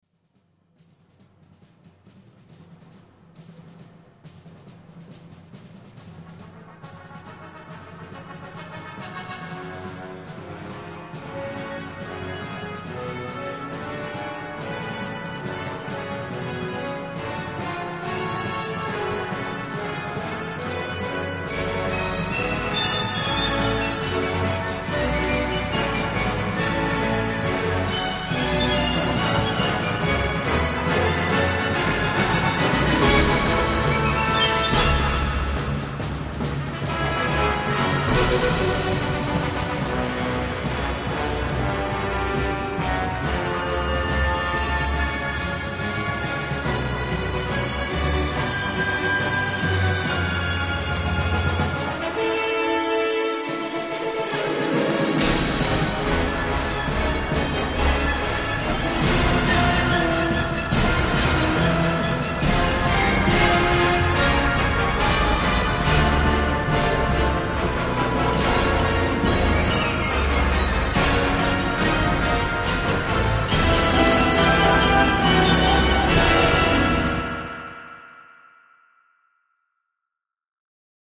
今回は非常に合唱がよい味を出してくれています。